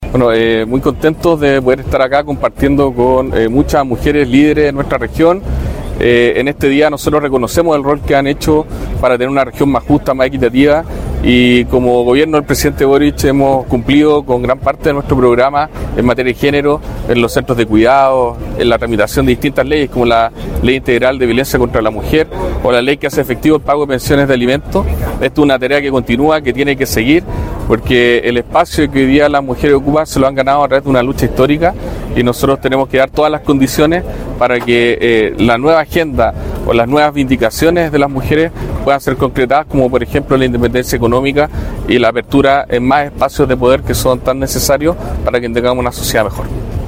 Ante más de 200 personas, entre ellas dirigentas, vecinas, artistas, académicas y autoridades, se conmemoró en el Faro Monumental de La Serena el acto oficial por el Día Internacional de la Mujer.
ACTO-8M-Delegado-Presidencial-Regional-Galo-Luna-Penna.mp3